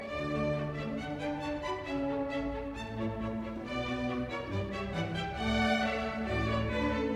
Effectif orchestral : 1 Flûte, 2 Hautbois, 2 bassons, 2 cors en ré, 2 trompettes en ré, timbales, quatuor à cordes (pas de clarinettes).
Interprétation choisie pour les extraits et pour l'écoute sur YouTube : Le London Symphony Orchestra dirigé par Istvan Kertész. Pianiste : Clifford Curzon.
I. ALLEGRO
Enoncé aux premiers violons, ce Sujet mélodique va être repris avec des appoggiatures et se conclure sur un petit motifs qui sera lui aussi réutilisé dans le mouvement.